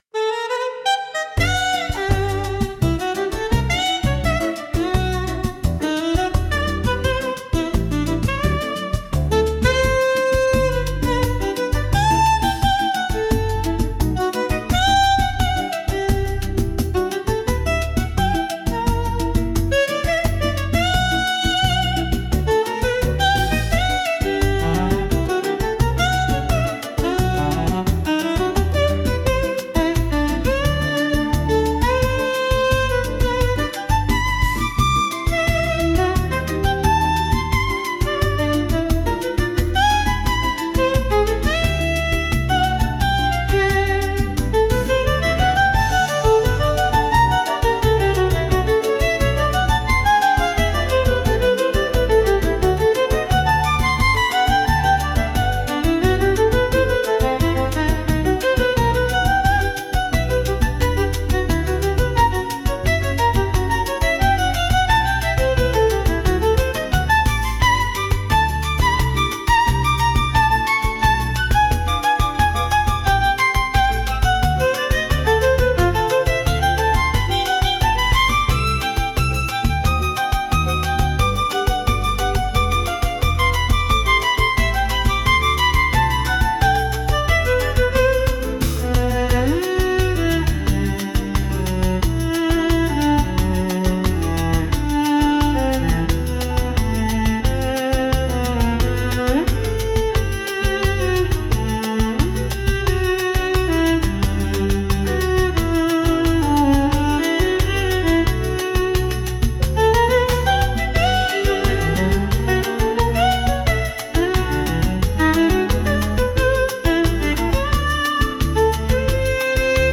instrumental 5